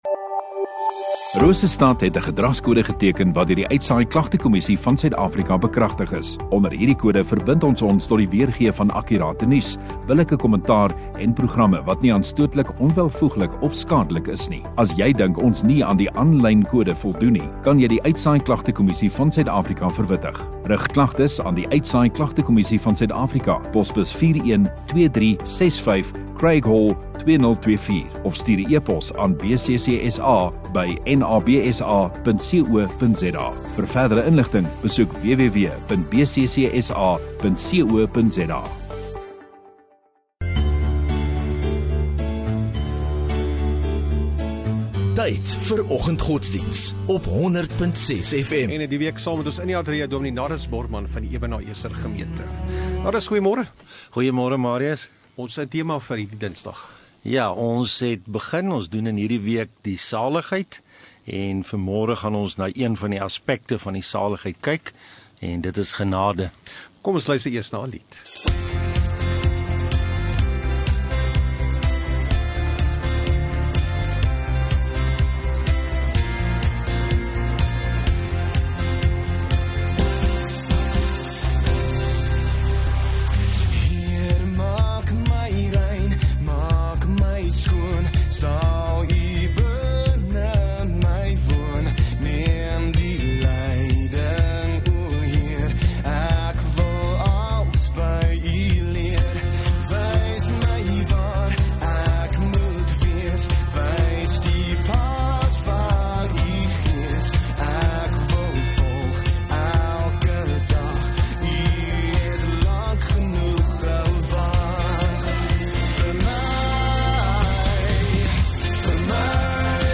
15 Oct Dinsdag Oggenddiens